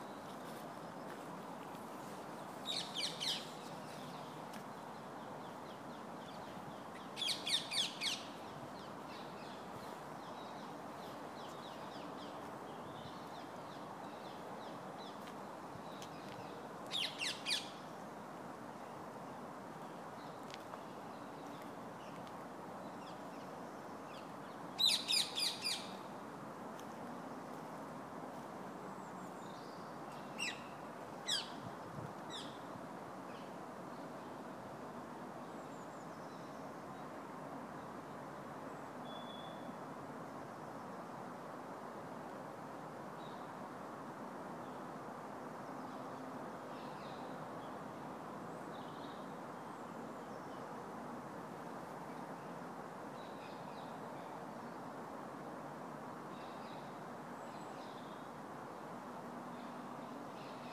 The parakeets are being lairy. There’s other sounds in the background: some liquid birdsong, the wisha-washa of the wind, the background rumble of tyres on tarmac.
Oxleas-Woods-23rd-Feb-14.m4a